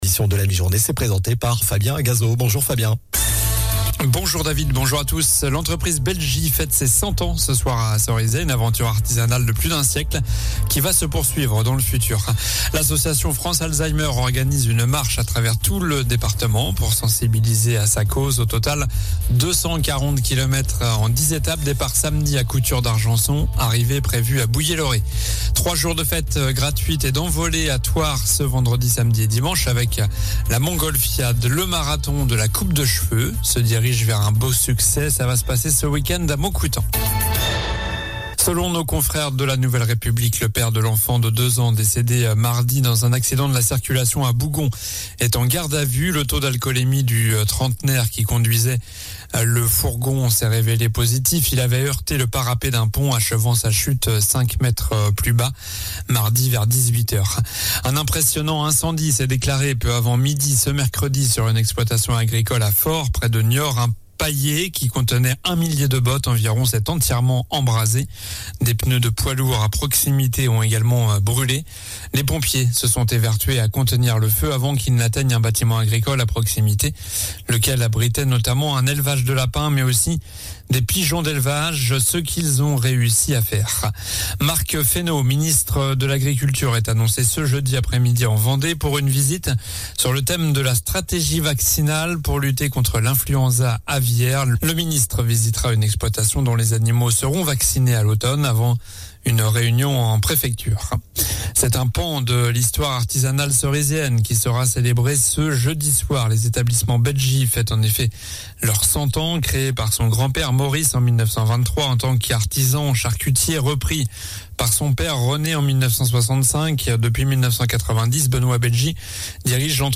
Journal du jeudi 31 août (midi)